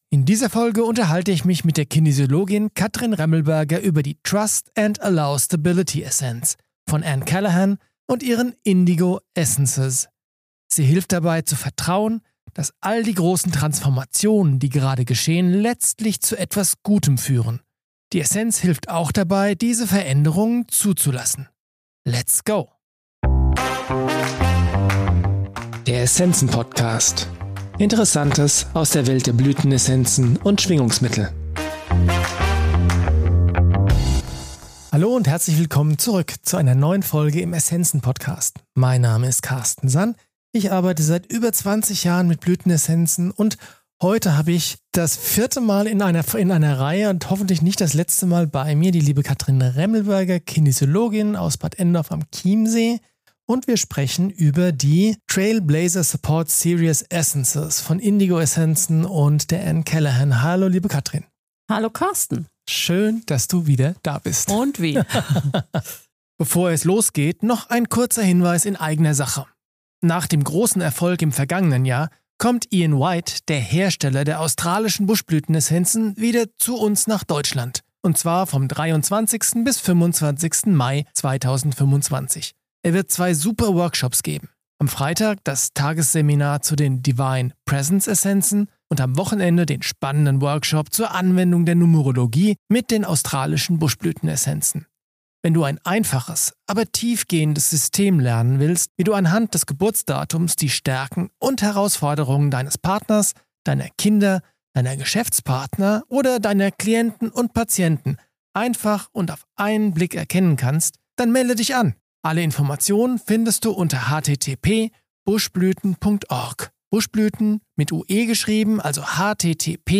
In dieser Folge unterhalte ich mich mit der Kinesiologin